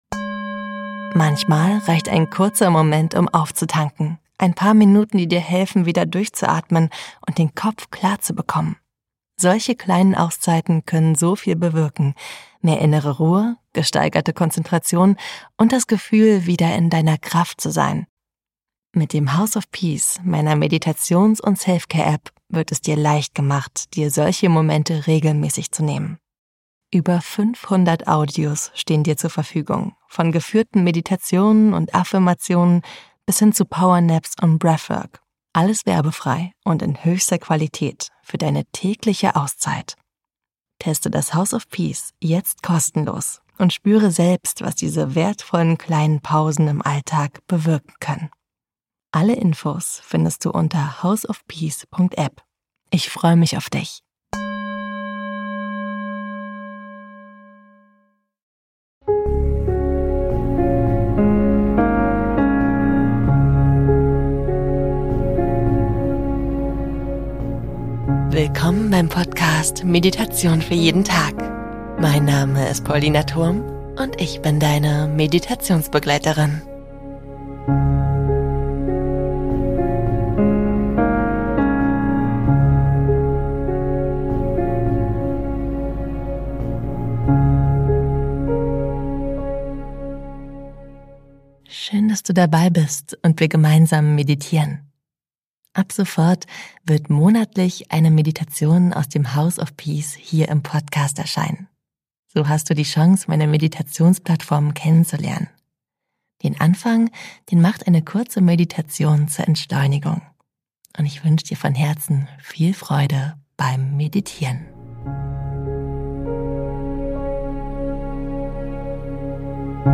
Meditation für jeden Tag - Dein Podcast für geführte Meditationen und Entspannung « » Meditation Nr. 210 // 6 Minuten zur Entschleunigung